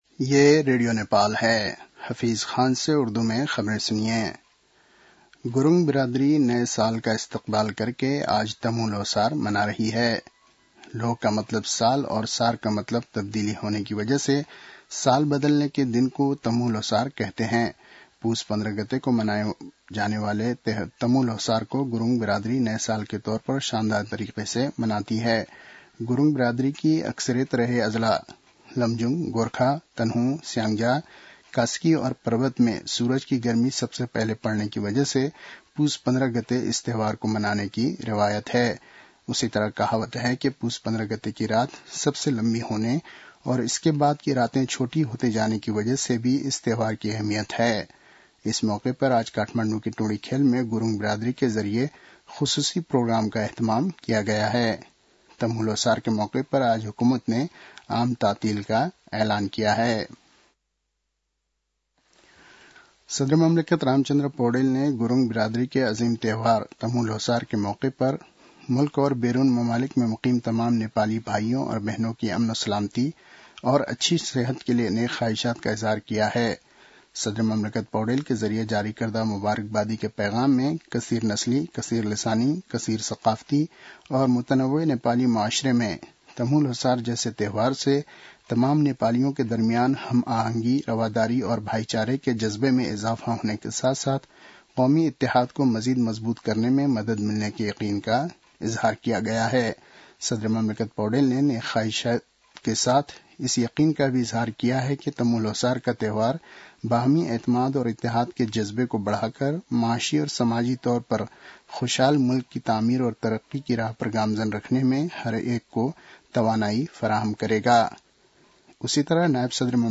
उर्दु भाषामा समाचार : १६ पुष , २०८१